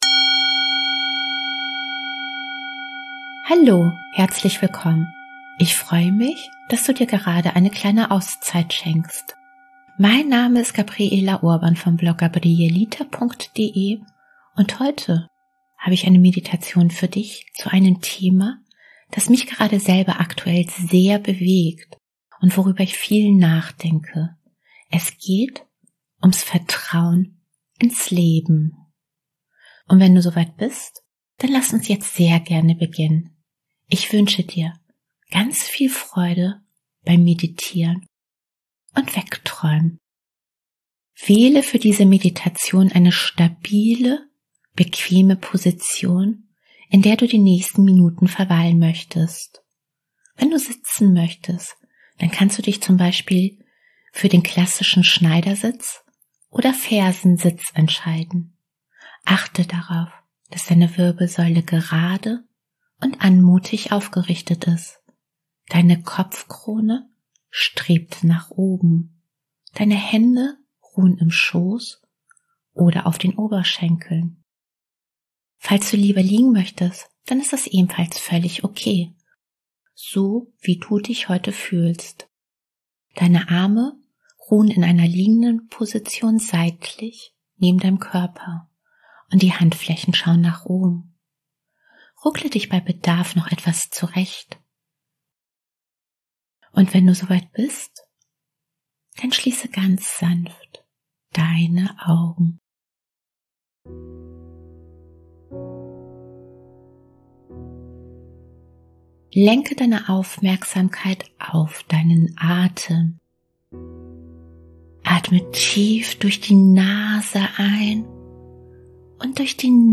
#028 Meditation Vertrauen ins Leben – Traumreise zu deinem Mandala